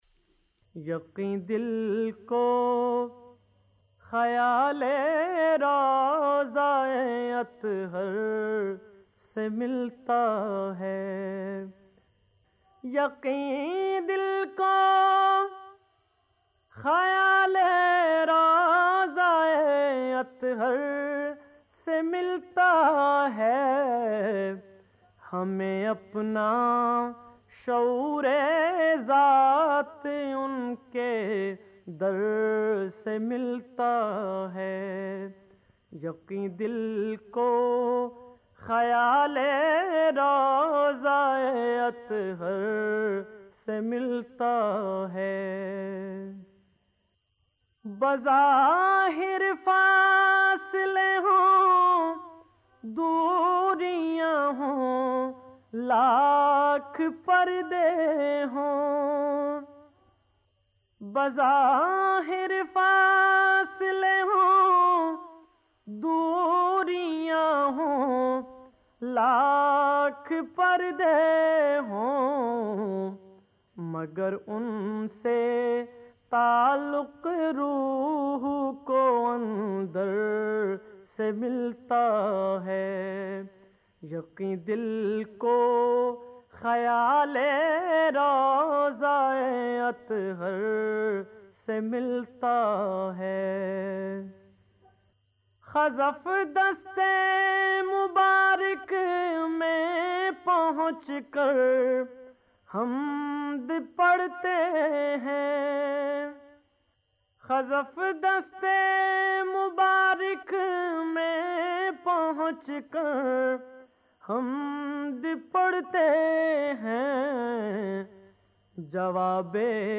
نعت رسول مقبول صلٰی اللہ علیہ وآلہ وسلم